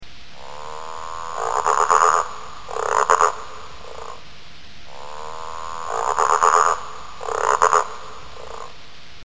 دانلود صدای قورباغه برای زنگ موبایل از ساعد نیوز با لینک مستقیم و کیفیت بالا
جلوه های صوتی
برچسب: دانلود آهنگ های افکت صوتی انسان و موجودات زنده دانلود آلبوم صدای قورباغه (قور قور) از افکت صوتی انسان و موجودات زنده